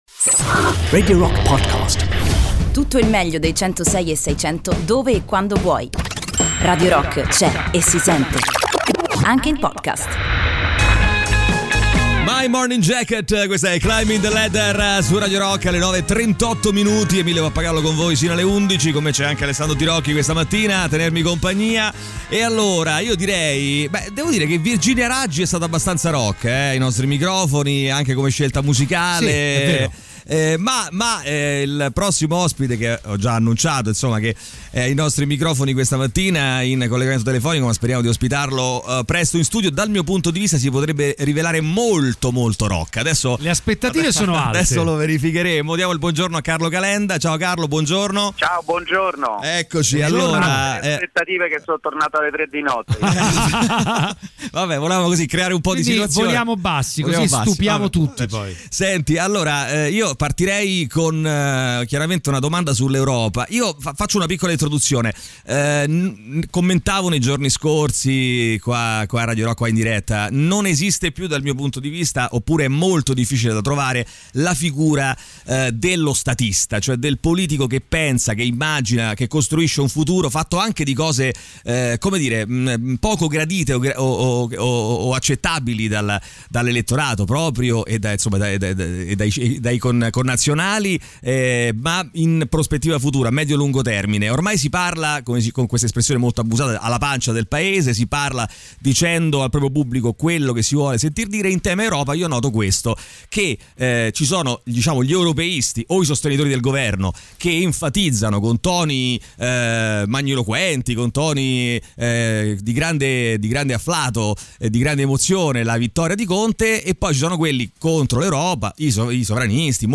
Intervista: "Carlo Calenda" (24-07-20)
in collegamento telefonico con Carlo Calenda durante il THE ROCK SHOW.